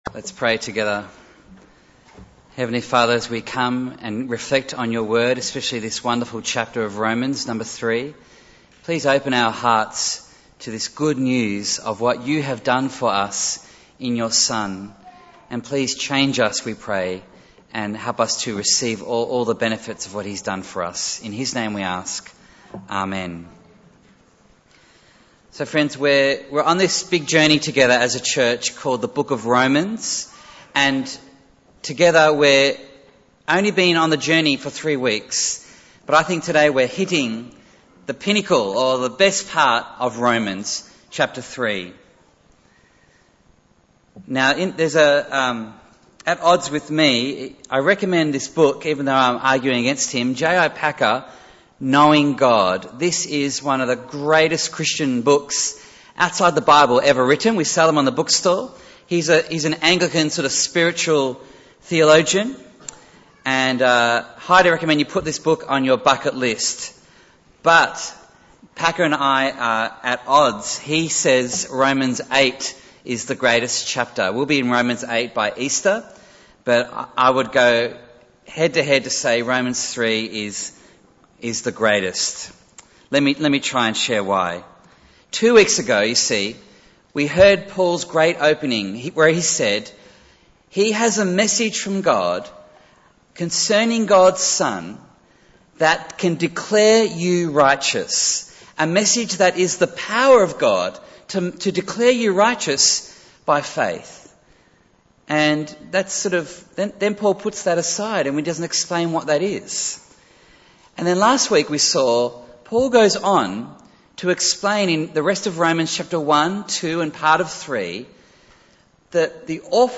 Bible Text: Romans 3:9-26 | Preacher